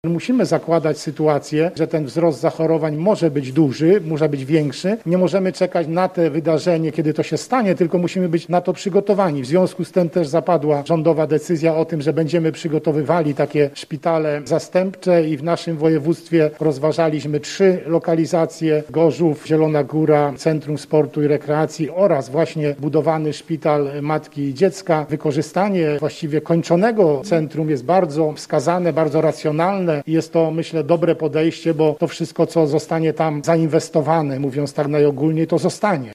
Marszałek województwa lubuskiego Elżbieta Polak mówi, że przyśpieszenie plac budowlanych w lecznicy jest dużym wyzwaniem: